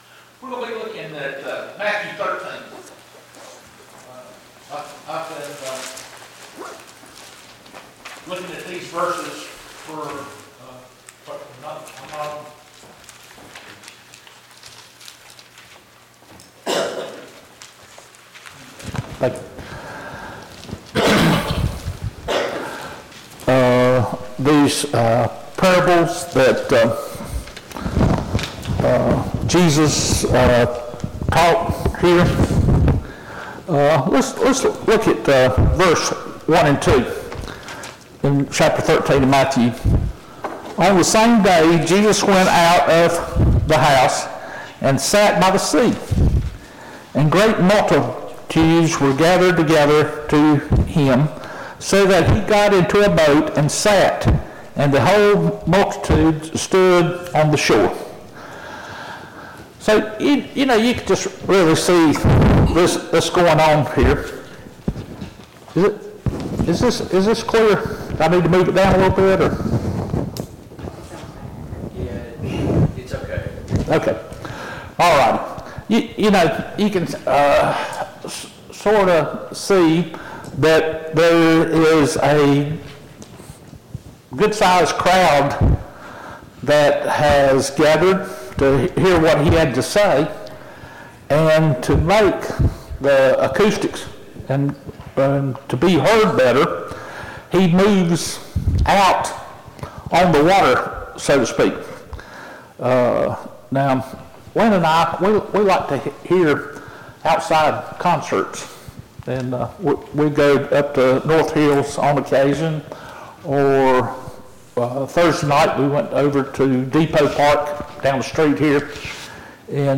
Passage: Matthew 13:31-32, Matthew 13:44-47 Service Type: Sunday Morning Bible Class